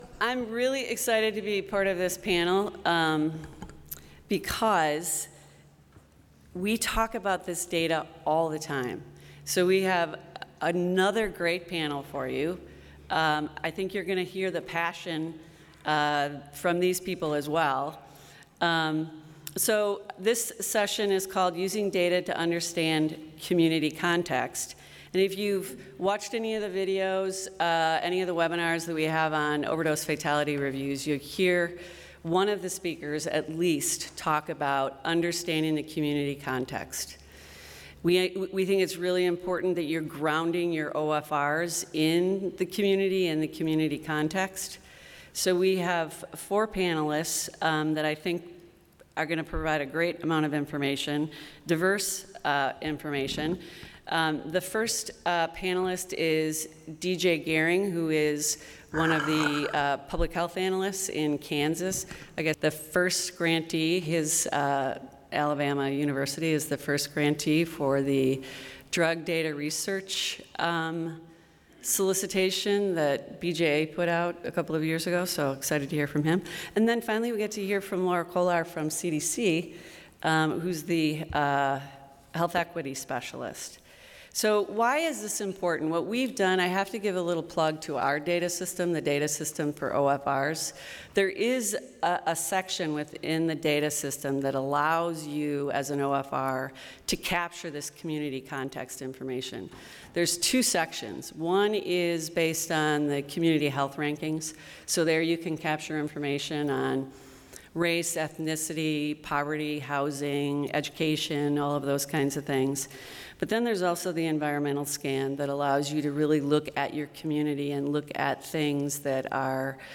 This presentation highlights four speakers and their work around using data to understand community context.